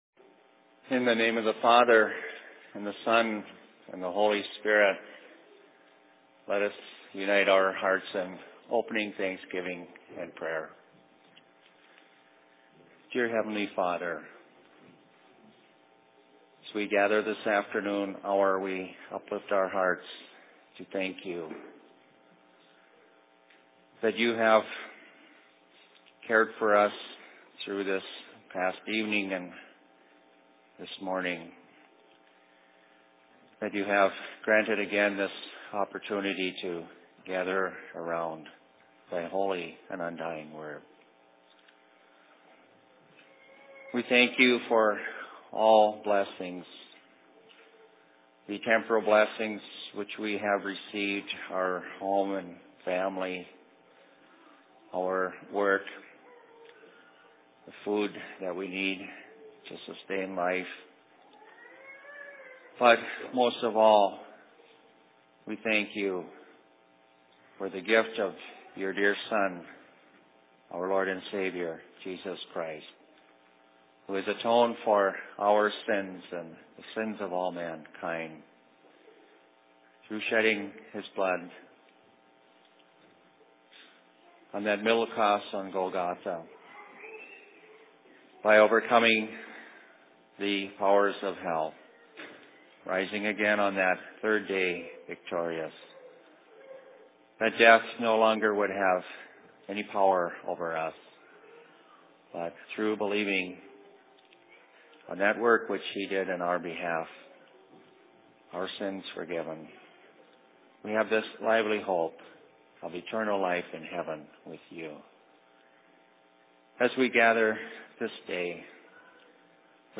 Sermon in Seattle 26.06.2010
Location: LLC Seattle